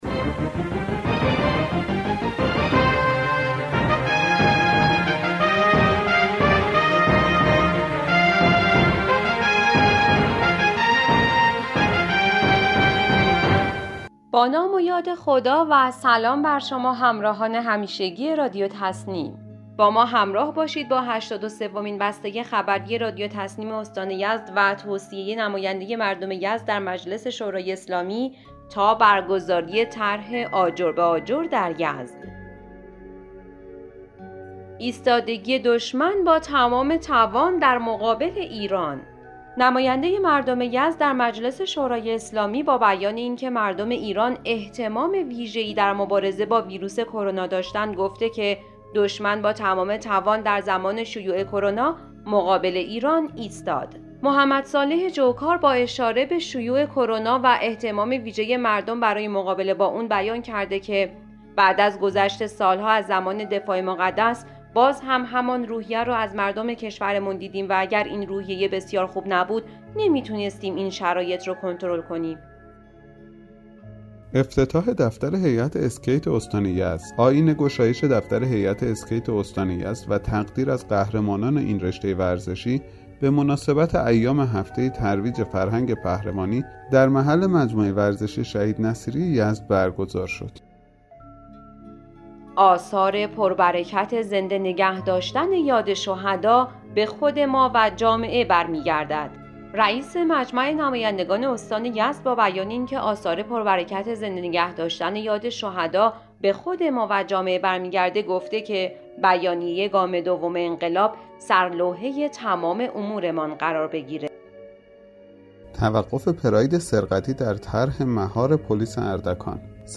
به گزارش خبرگزاری تسنیم از یزد, هشتاد و سومین بسته خبری رادیو تسنیم استان یزد با خبرهایی از توصیه نماینده مردم یزد در مجلس شورای اسلامی درمورد ایستادگی دشمن, افتتاح دفتر هیئت اسکیت استان یزد، تاکید رئیس مجمع نمایندگان استان در مورد زنده نگاه داشتن یاد شهدا, کشف پراید سرقتی در اردکان، توزیع 1200 بسته معیشتی در بین نیازمندان اشکذری، پرداخت وام حمایتی مشاغل آسیب دیده کرونا از هفته آینده و برگزاری طرح آجر به آجر در یزد منتشر شد.